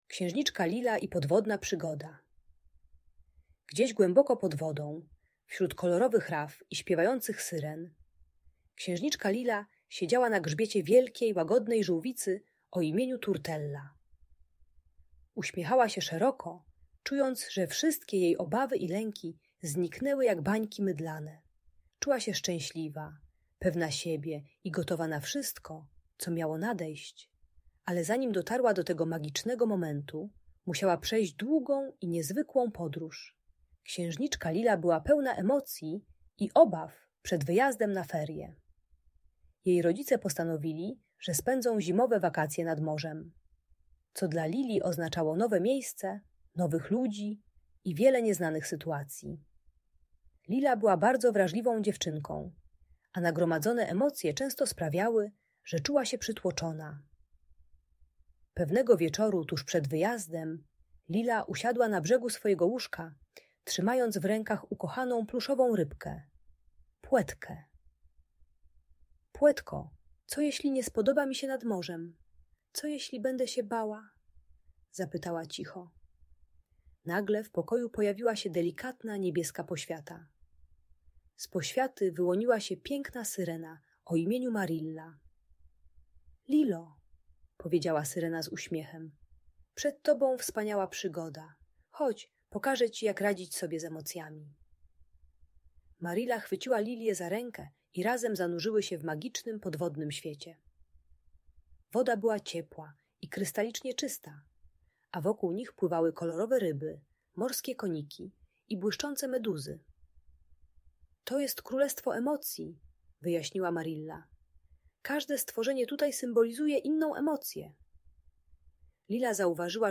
Podwodna przygoda księżniczki Lili - historia pełna emocji - Emocje rodzica | Audiobajka